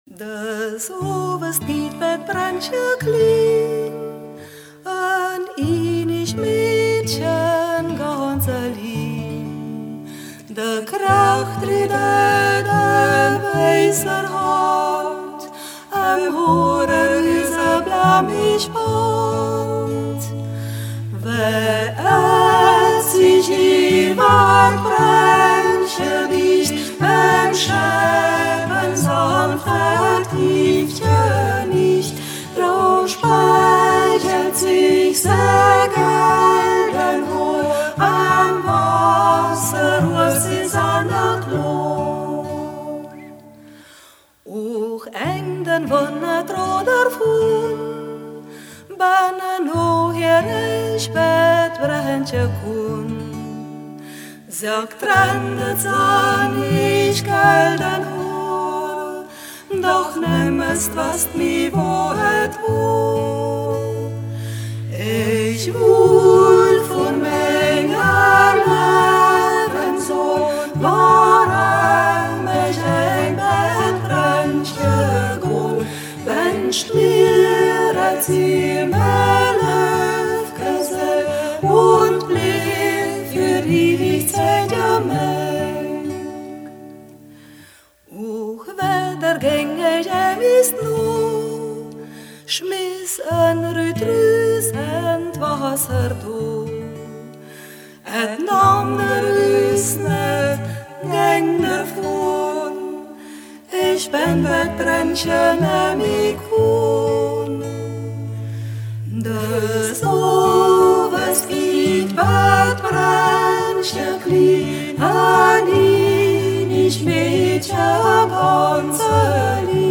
Umgangssächsisch
Ortsmundart: Mediasch